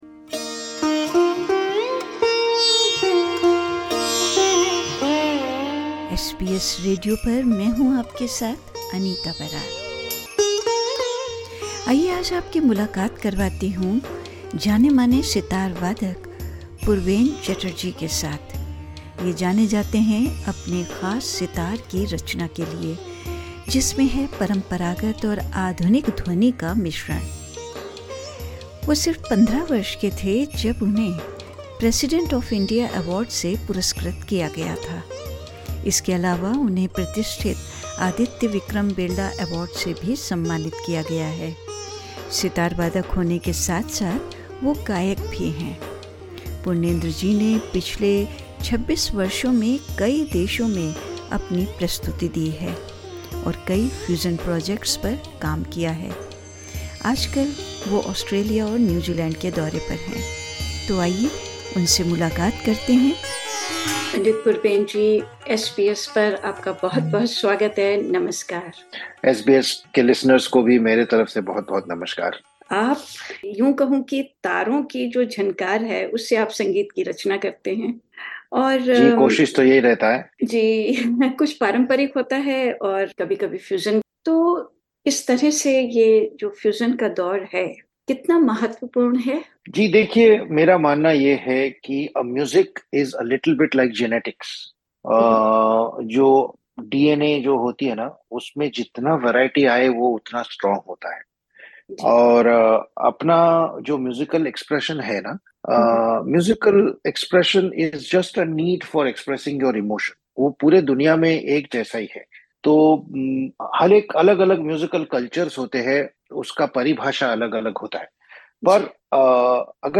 In an exclusive interview with SBS Hindi, he emphasised the significance of embracing liberation and collaborating with diverse musical cultures, all while upholding the tradition and sensitivity of classical music in the contemporary 'zen age.'